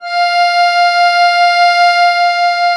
MUSETTESW.13.wav